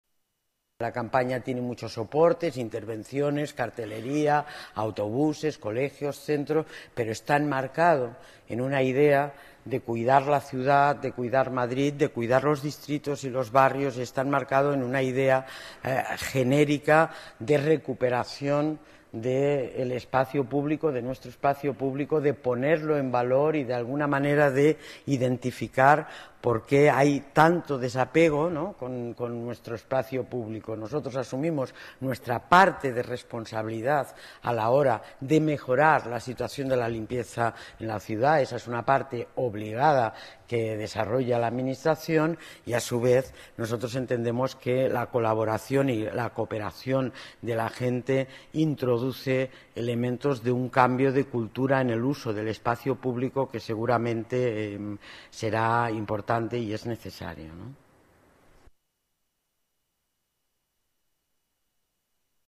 La delegada de Medio Ambiente y Movilidad, Inés Sabanés, presenta la campaña de sensibilización